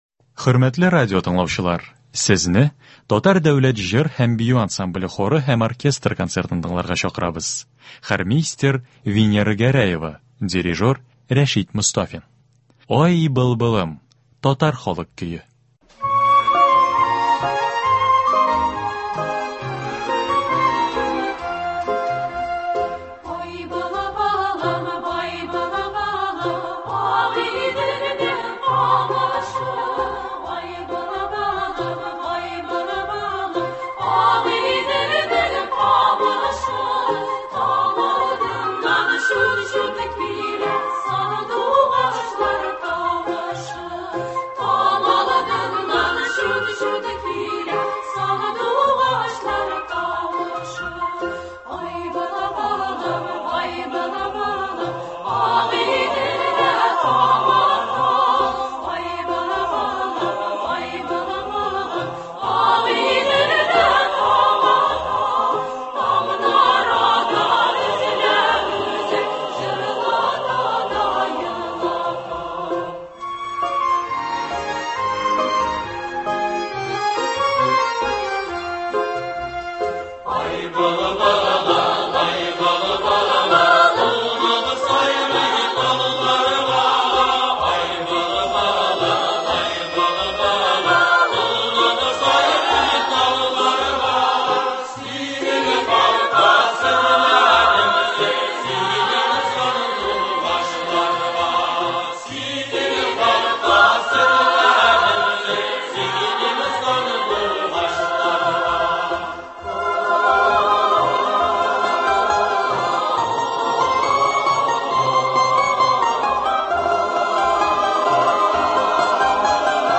Кичке концерт. Татар дәүләт җыр һәм бию ансамбле концерты.